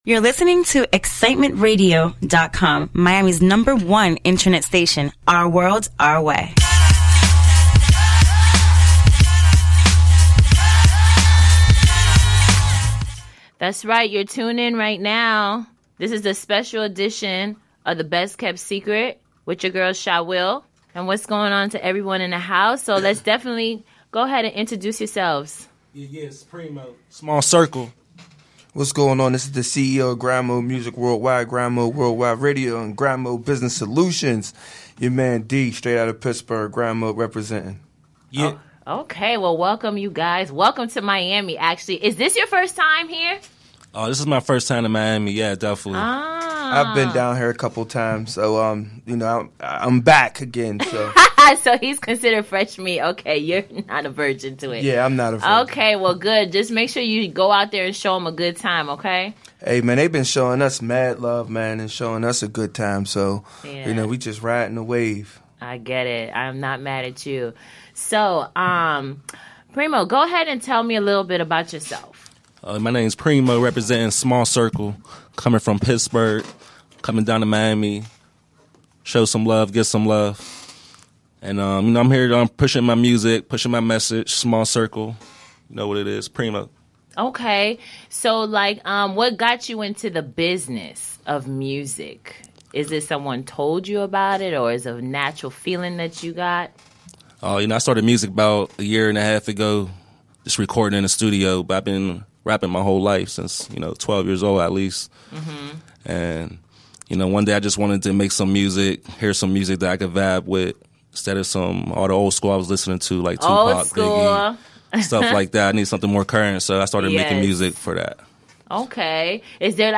Showcasing Independent Artist from all over the world.